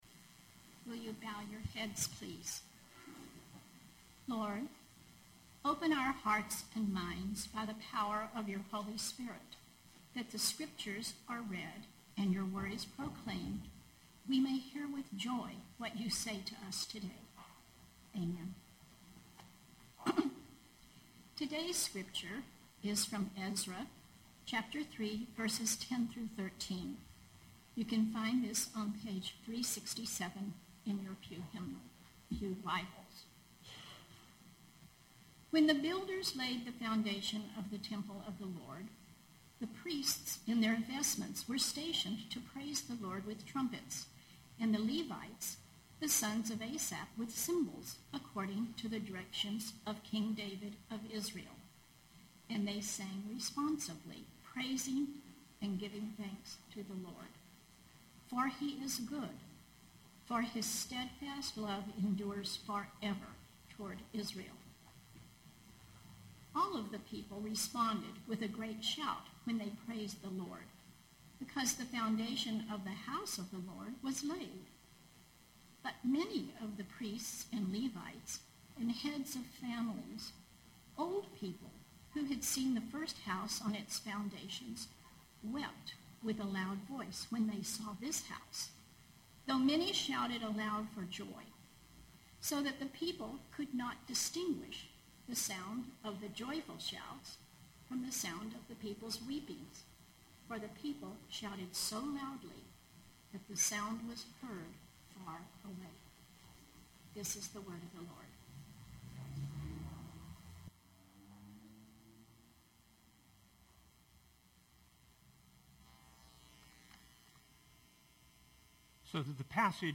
Knox Pasadena Sermons Shouts of Joy and Tears of Sorrow Sep 14 2025 | 00:22:45 1x 00:00 / 00:22:45 Subscribe Share Spotify RSS Feed Share Link Embed